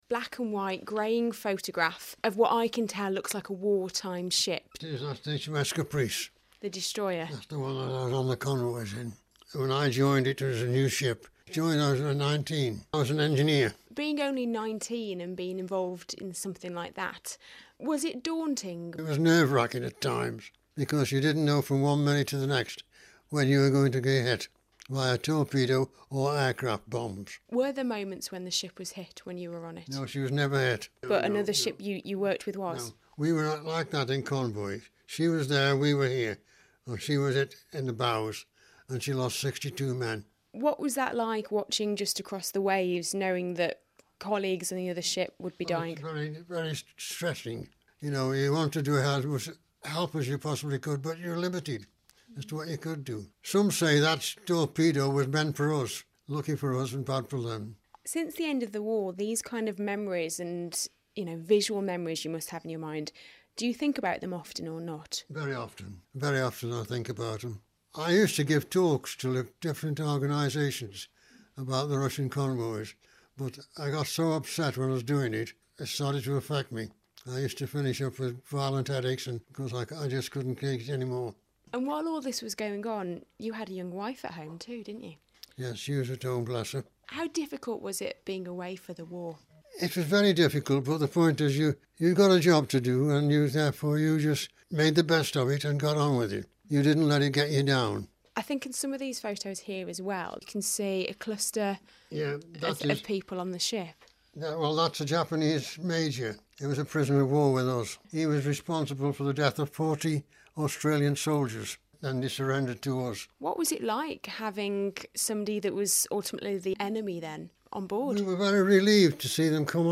(Broadcast on BBC Radio Stoke, May 2016)